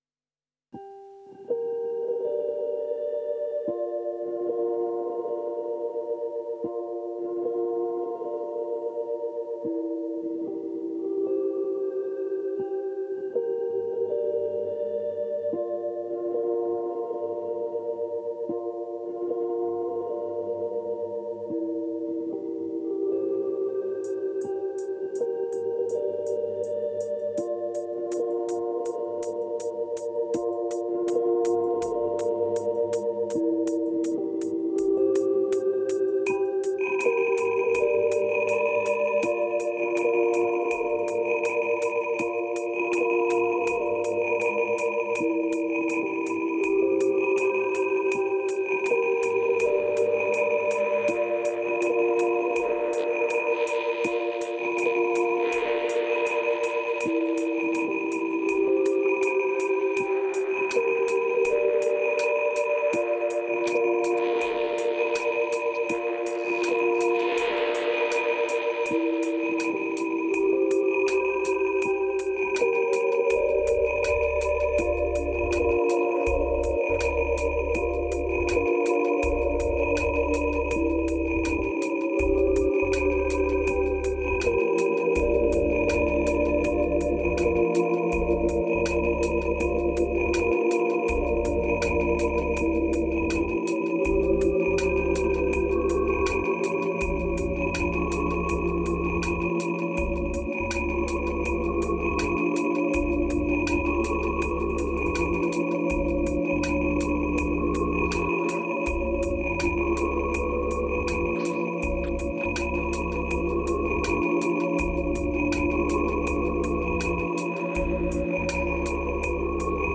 2300📈 - 86%🤔 - 81BPM🔊 - 2017-04-07📅 - 686🌟